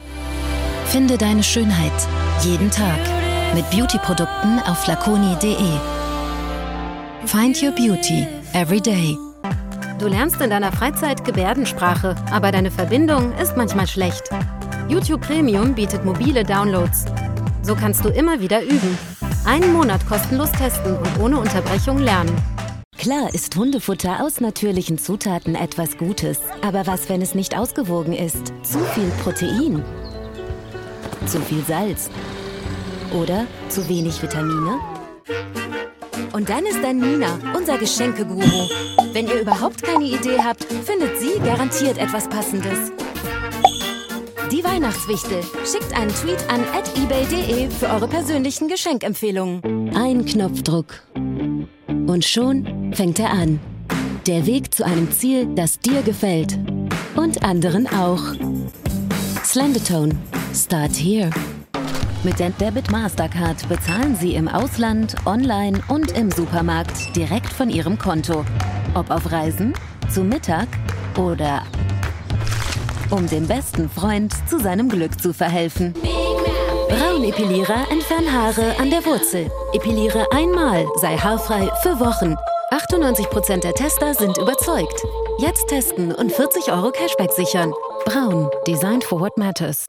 klar, angenehm und freundlich, mit einer weiten emotionalen Bandbreite
Sprechprobe: Werbung (Muttersprache):
clear, pleasant, friendly and reassuring with a good emotional range